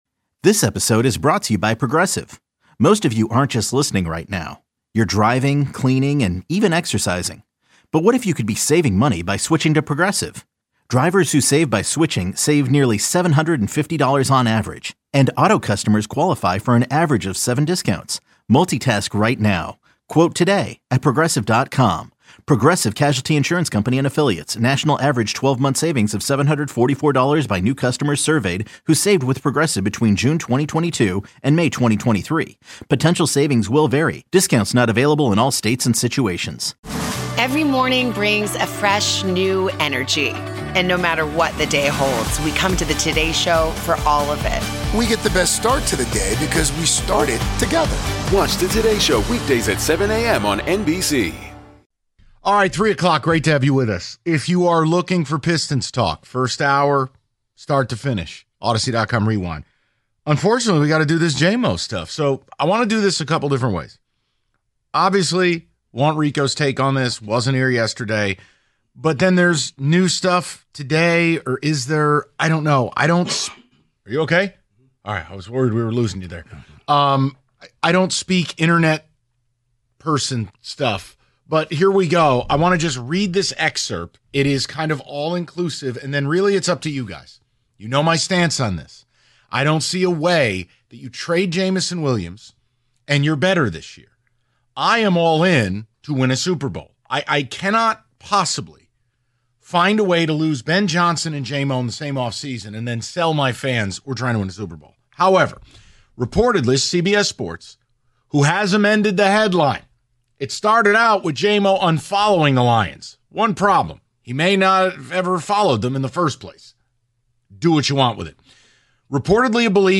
They wonder what the fallout from this will be and whether Brad Holmes plans on trading the star WR or not. They take a bunch of your calls and read your ticket texts on the topic throughout the hour.